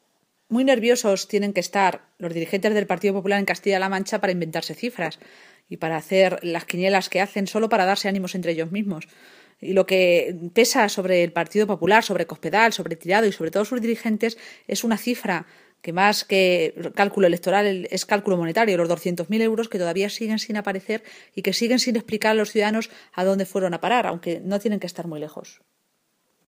La portavoz socialista contesta a Tirado que sus “cábalas” sobre los resultados electorales del 24-M están muy lejos de la realidad.
Cortes de audio de la rueda de prensa